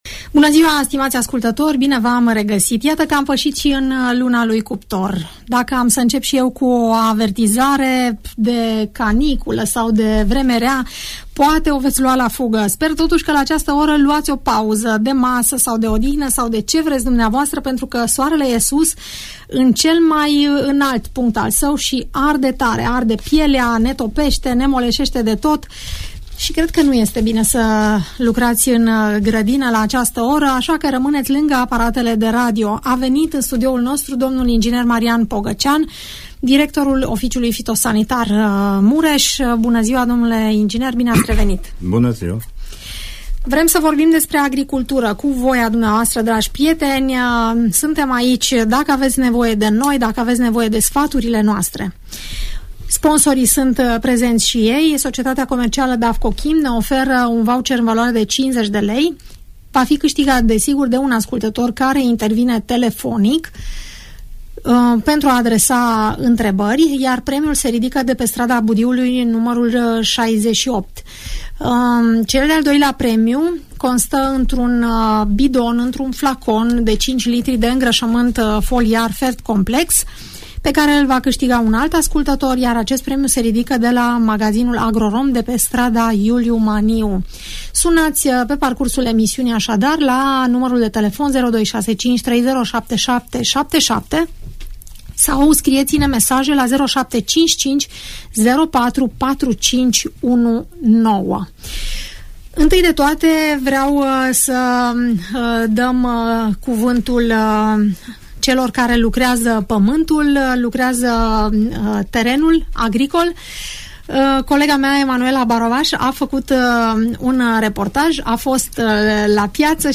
Care sunt lucrările agricole care trebuie efectuate în luna iulie și cum protejăm plantele de boli și dăunători în această perioadă a anului, aflați din emisiunea „Părerea ta”, difuzată la Radio Tg. Mureș.